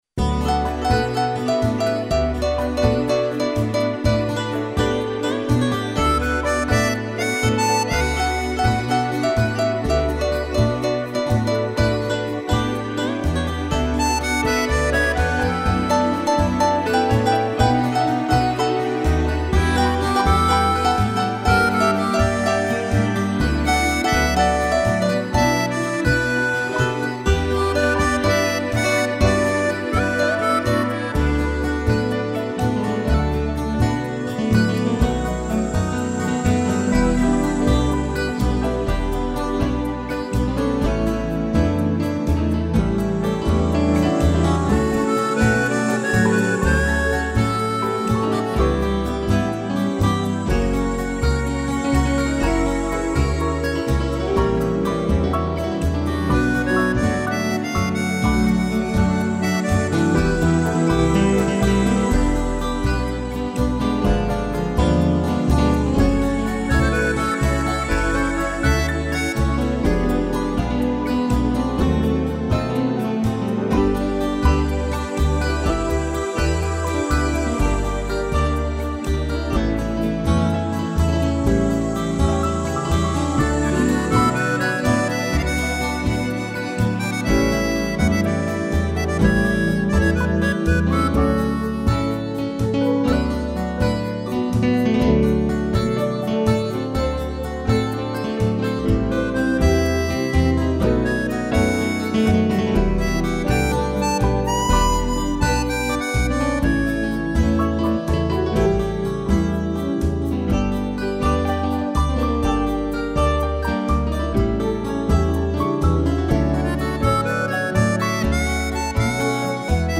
piano e gaita
(instrumental)